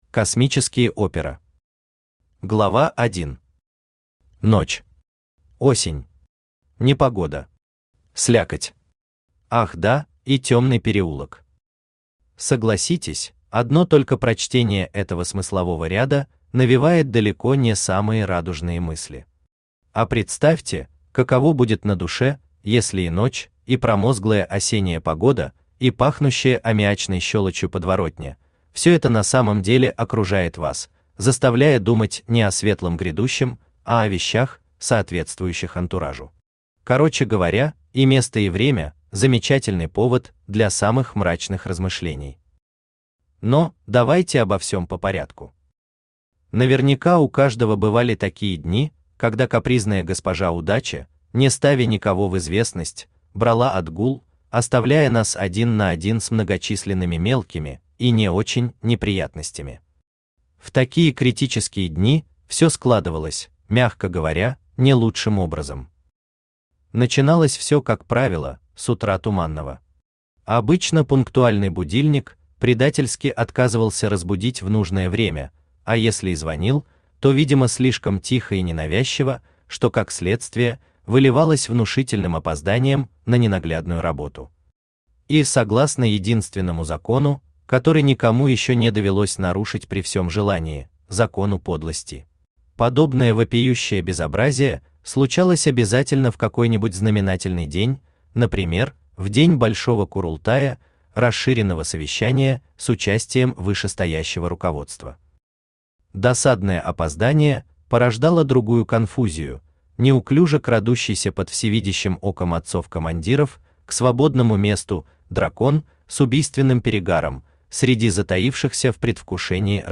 Аудиокнига Космические опера | Библиотека аудиокниг
Aудиокнига Космические опера Автор Шмигалев Николай Николаевич Читает аудиокнигу Авточтец ЛитРес.